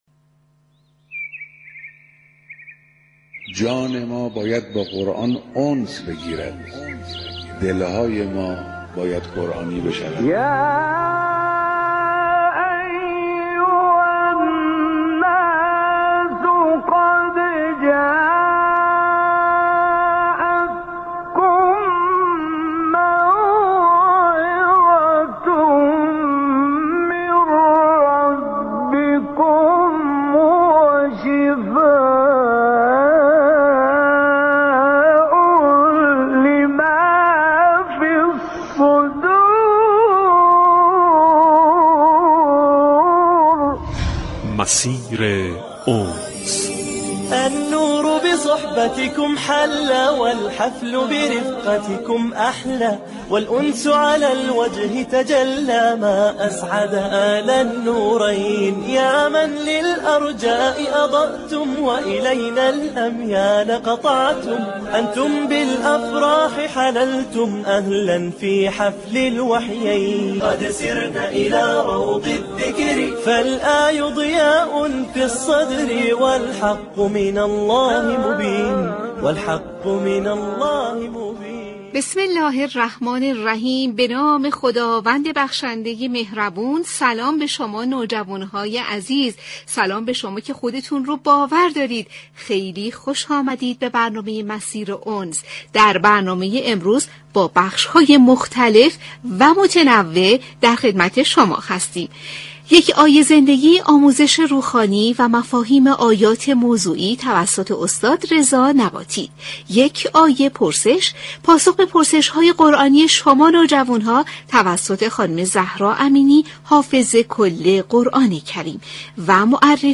مسیر انس روز پنجشنبه دوم تیر ماه نیز همچون برنامه های قبل با حضور نوجوانان حافظ قرآن كریم همراه بود.